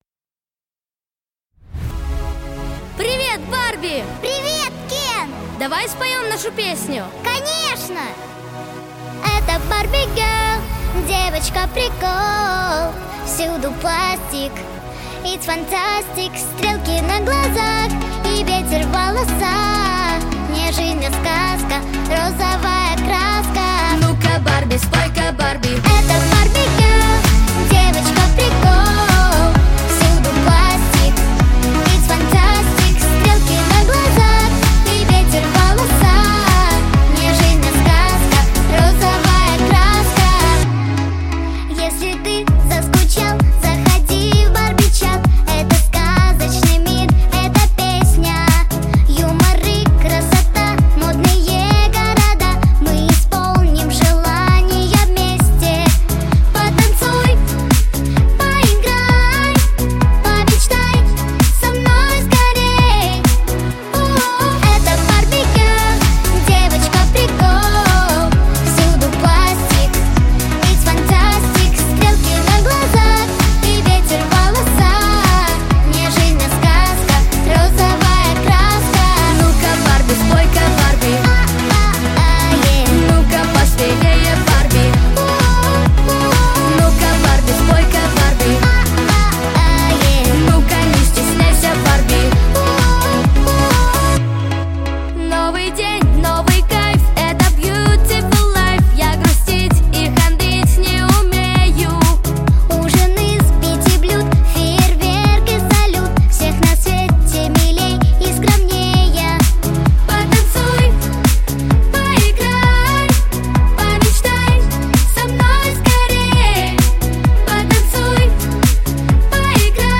детского хора